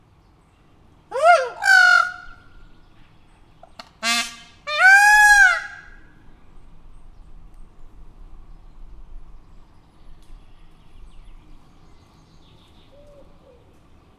Directory Listing of /_MP3/allathangok/pecsizoo2017_professzionalis/pava/